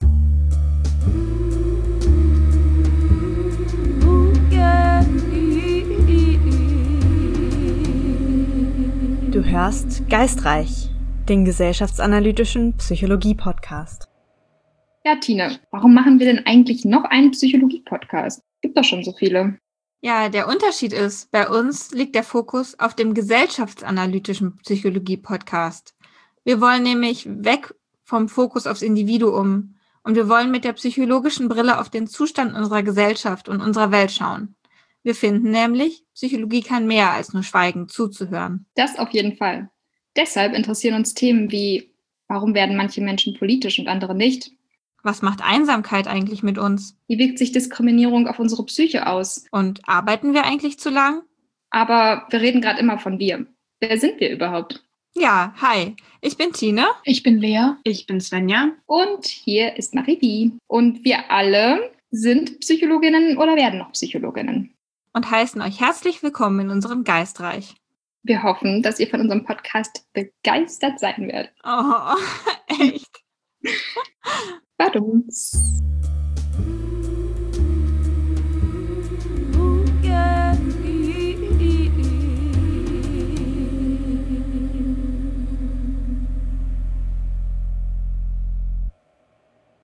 Wir sind ein Team von Psycholog:innen, das sich zusammengefunden hat, weil wir eine Begeisterung teilen: Wir möchten unsere Erkenntnisse aus der psychologischen Theorie und Praxis nutzen, um wichtige gesellschaftliche und politische Fragen und ihre Bedeutung für unser Wohlbefinden zu beleuchten.